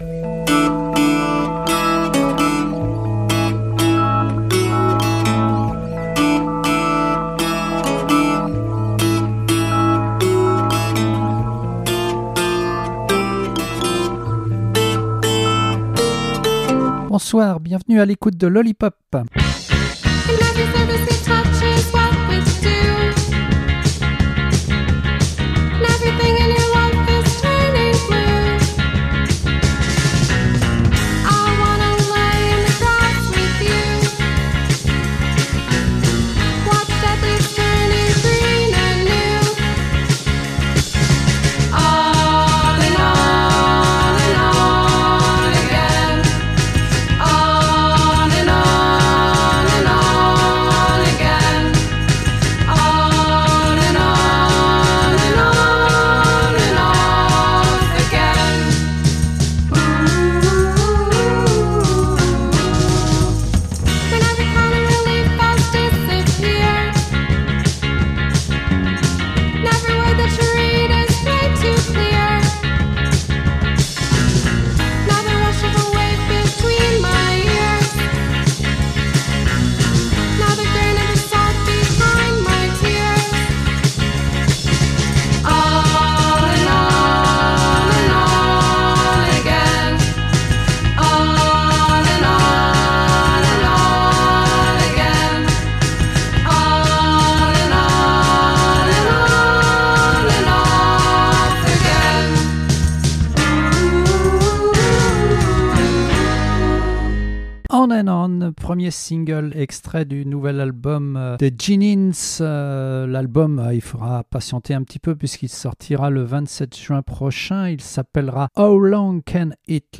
Toutes les nouveautés pop et indie pop du moment